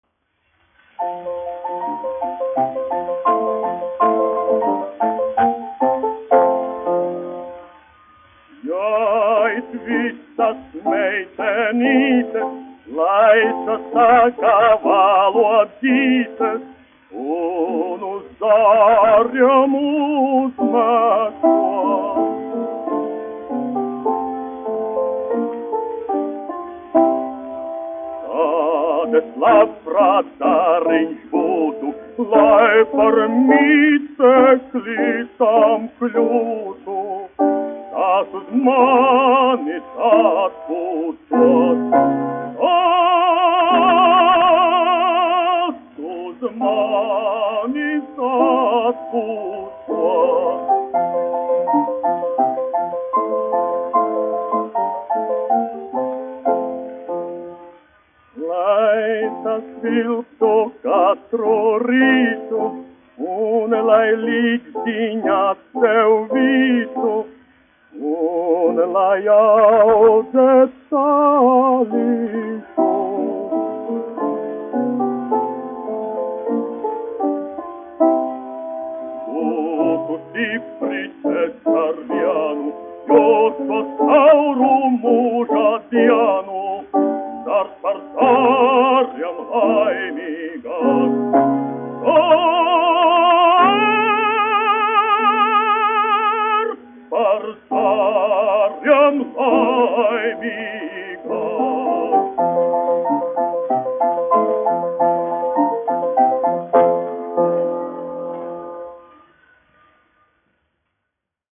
Kaktiņš, Ādolfs, 1885-1965, dziedātājs
1 skpl. : analogs, 78 apgr/min, mono ; 25 cm
Operas--Fragmenti, aranžēti
Skaņuplate